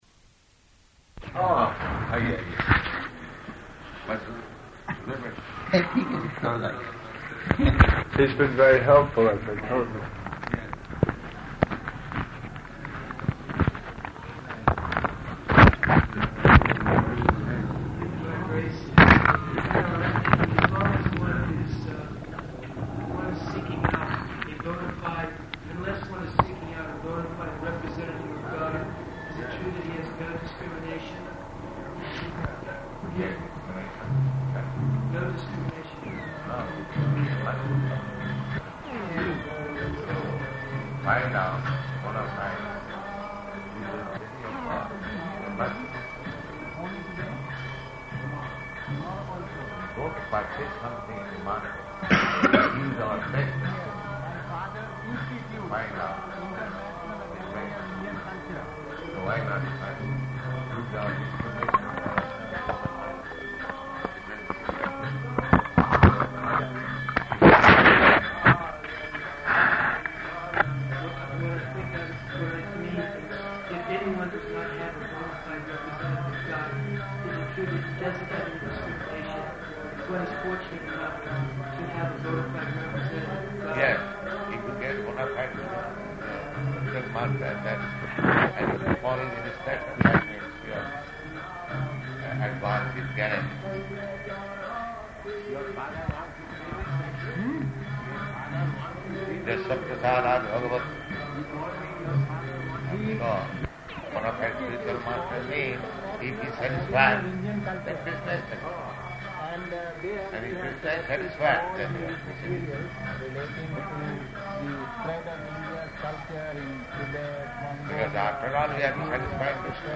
Room Conversation
Room Conversation --:-- --:-- Type: Conversation Dated: December 3rd 1971 Location: Delhi Audio file: 711203R1-DELHI.mp3 [Mixed Recordings] Prabhupāda: [Hindi] Aiye, aiye.